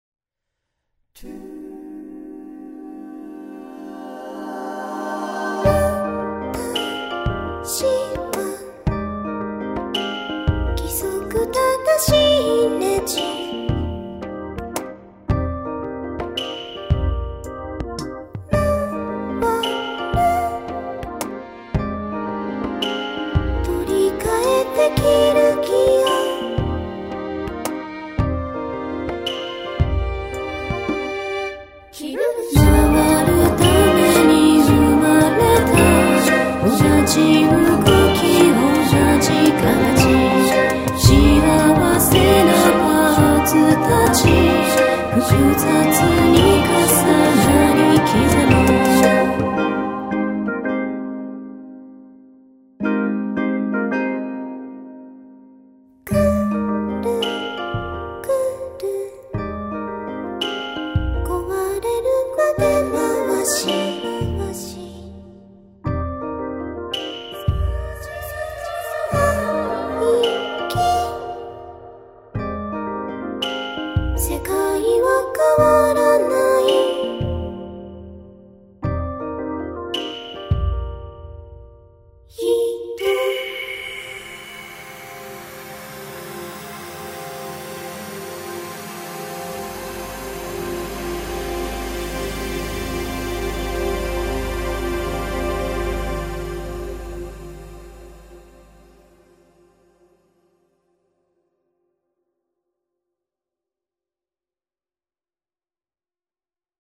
人間版